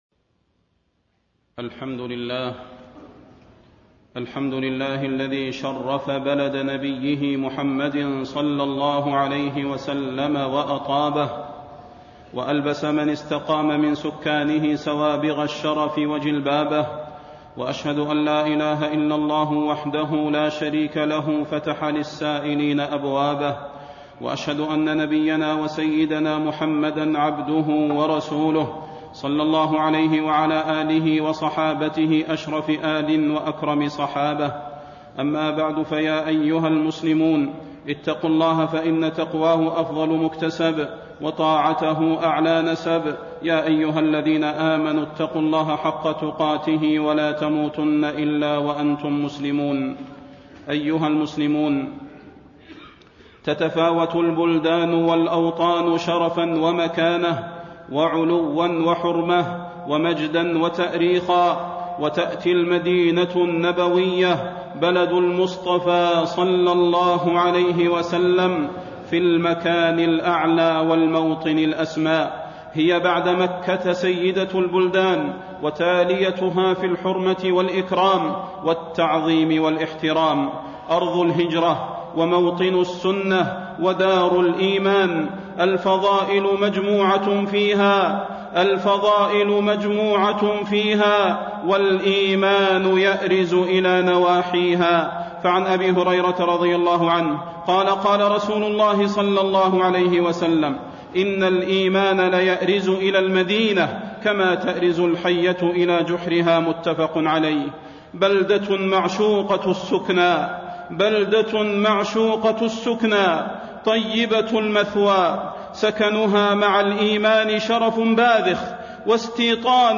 تاريخ النشر ١١ رجب ١٤٣٣ هـ المكان: المسجد النبوي الشيخ: فضيلة الشيخ د. صلاح بن محمد البدير فضيلة الشيخ د. صلاح بن محمد البدير فضل المدينة والمسجد النبوي